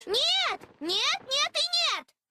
Мейбл Пайнс твердит Нет нет и нет девочка